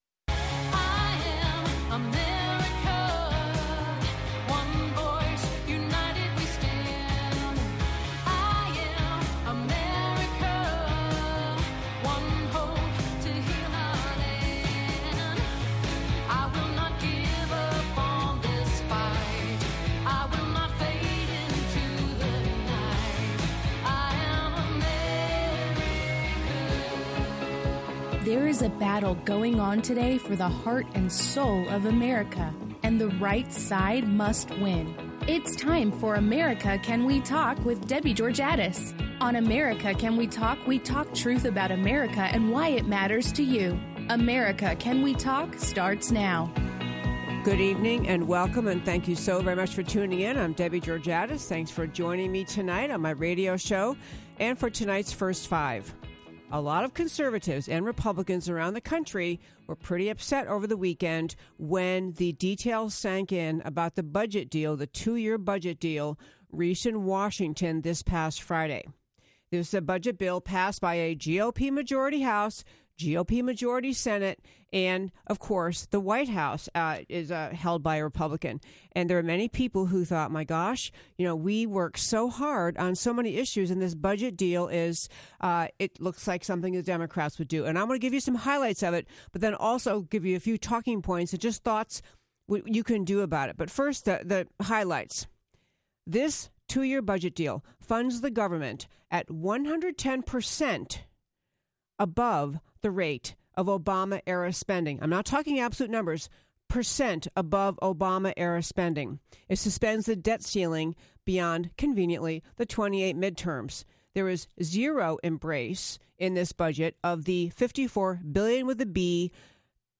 Guest interview – Gordon Chang, Internationally Renowned Expert on China, North Korea